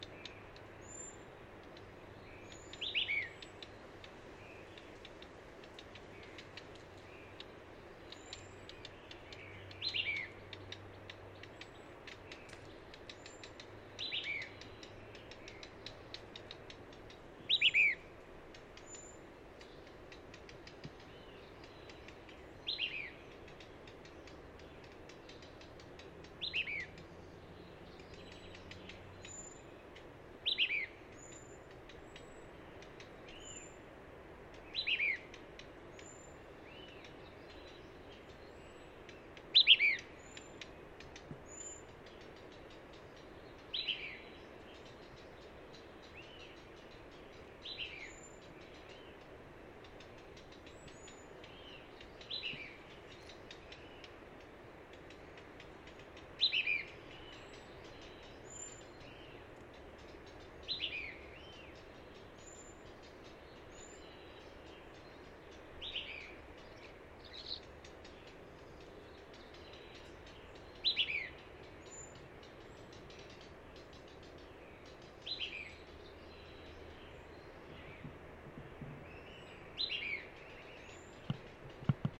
Fiofío Silbón (Elaenia albiceps)
Nombre en inglés: White-crested Elaenia
Fase de la vida: Adulto
Localidad o área protegida: Parque Nacional Nahuel Huapi
Condición: Silvestre
Certeza: Observada, Vocalización Grabada